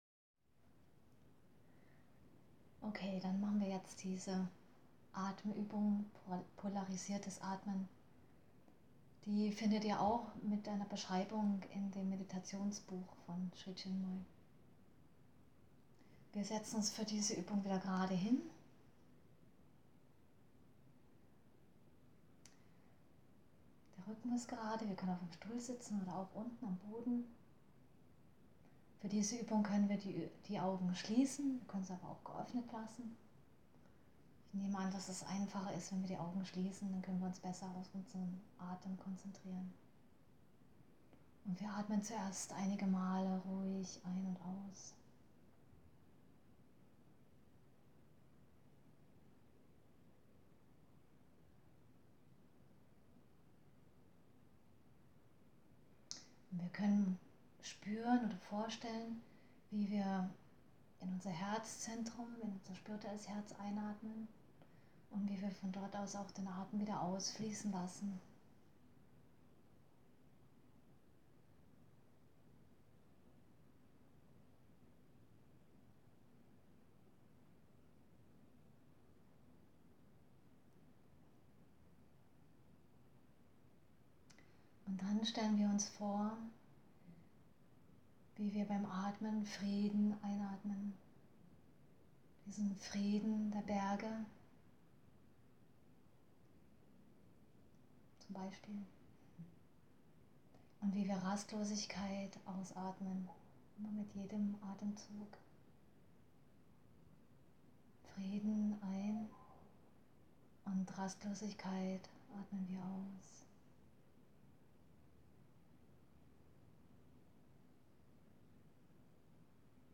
Podcast: Meditation lernen mit angeleiteten Übungen – Teil 1 | Radio Sri Chinmoy